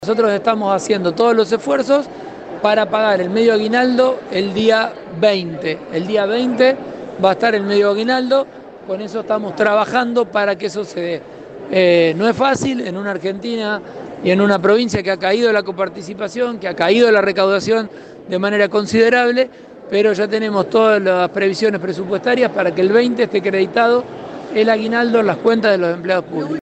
En el agasajo por el día del periodista, Pullaro dijo que hacen un gran esfuerzo para que el día 20 el dinero esté reflejado en las cuentas.